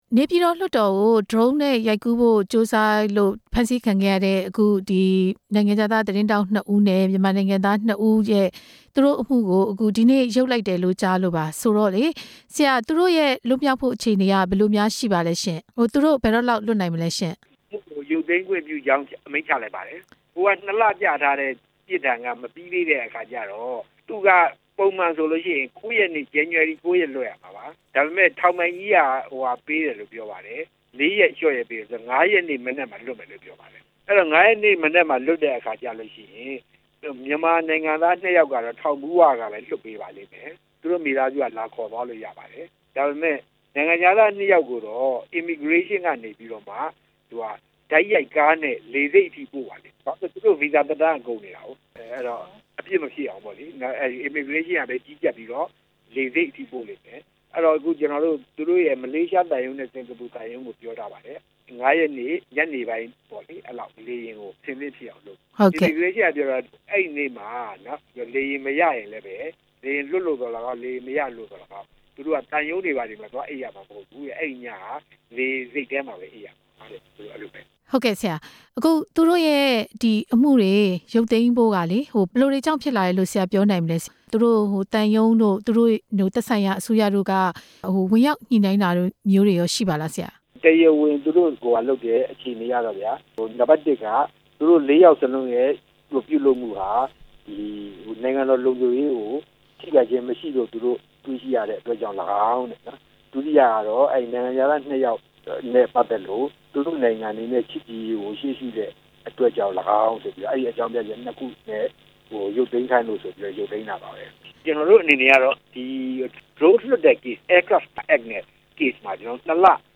မောင်းသူမဲ့ယာဉ်ငယ်အသုံးပြု သတင်းထောက်တွေအမှု ရှေ့နေနဲ့ မေးမြန်းချက်